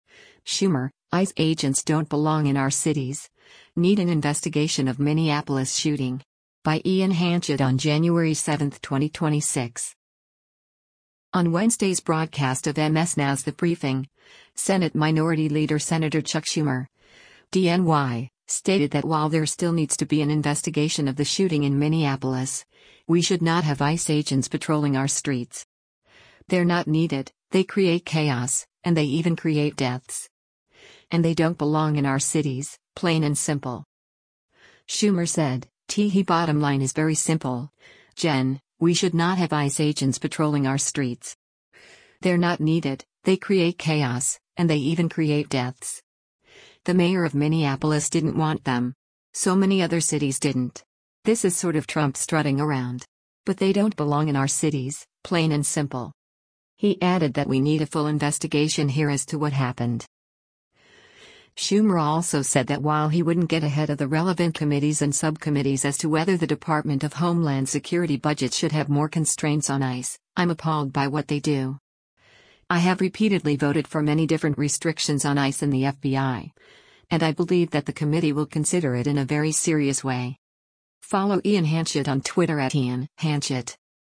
On Wednesday’s broadcast of MS NOW’s “The Briefing,” Senate Minority Leader Sen. Chuck Schumer (D-NY) stated that while there still needs to be an investigation of the shooting in Minneapolis, “We should not have ICE agents patrolling our streets. They’re not needed, they create chaos, and they even create deaths.”